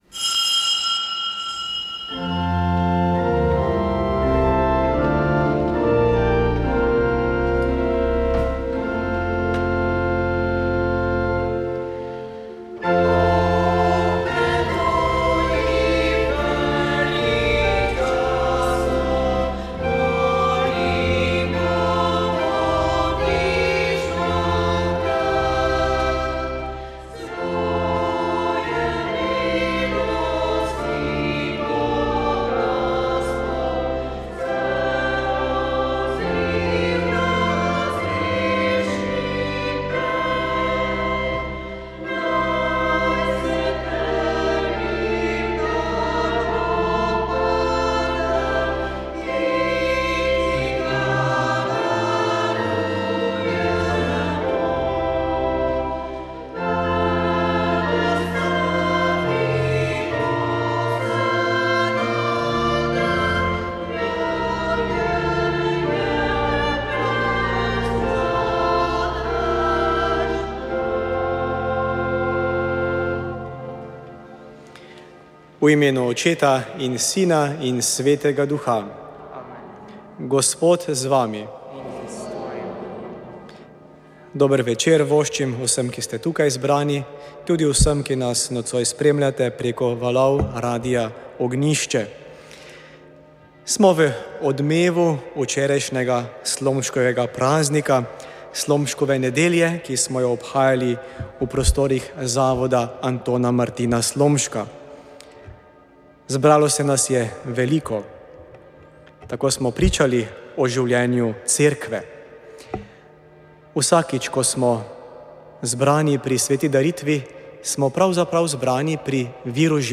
Sv. maša iz bazilike Marije Pomagaj na Brezjah 30. 5.